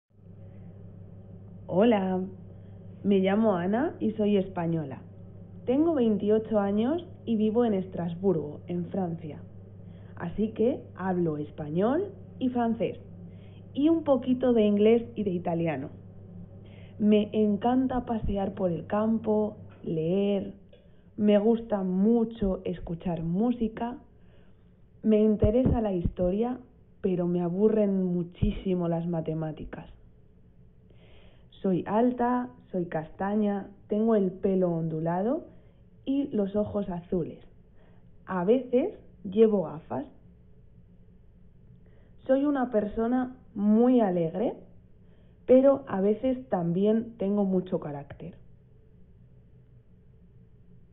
Escucha la presentación de la profesora